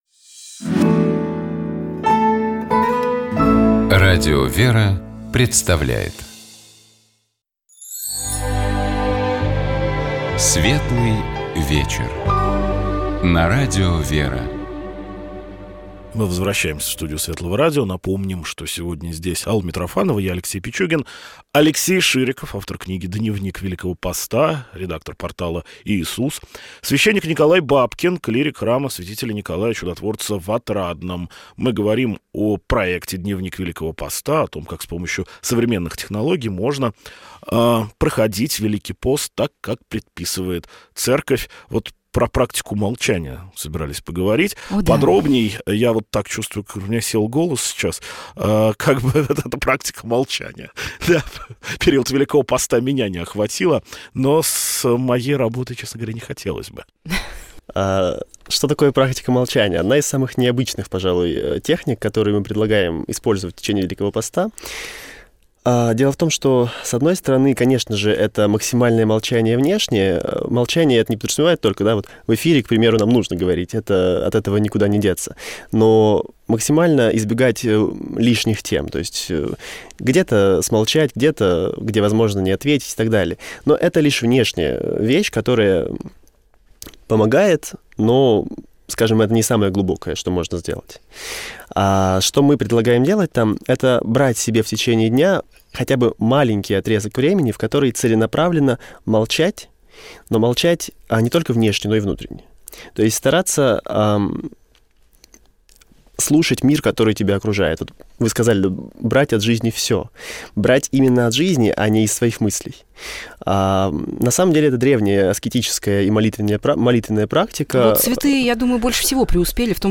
Наши гости поделились, что пост - это дни великой радости, и важно уметь это увидеть, важно ставить перед собой определенные цели по изменению себя и своих дел. Также разговор шел о детской книге о посте: чем она может заинтересовать подрастающее поколение.